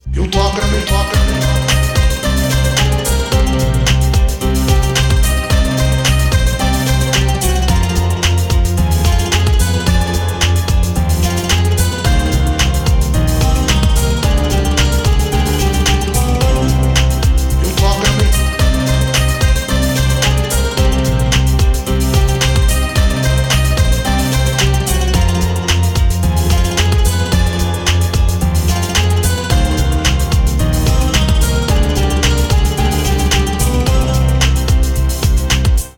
• Качество: 320, Stereo
Ремикс на популярную инструментальную композицию